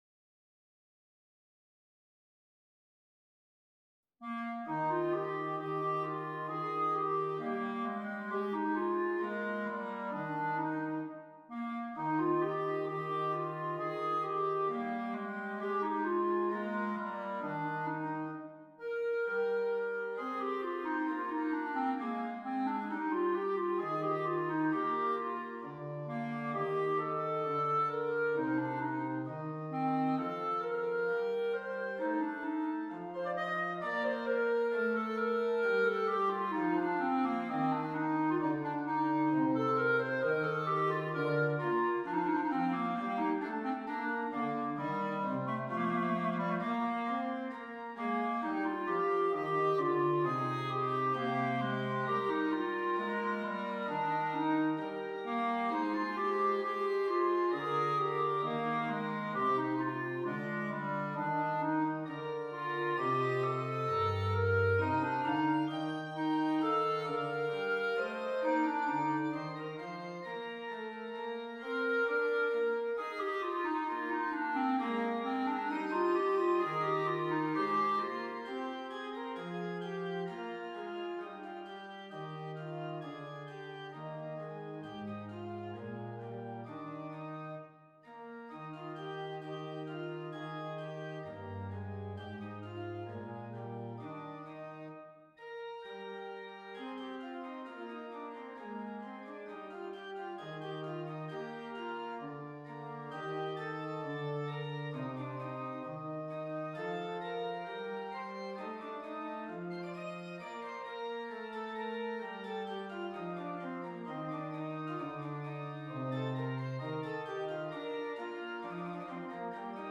Clarinet and Keyboard
solo clarinet and keyboard (piano or organ)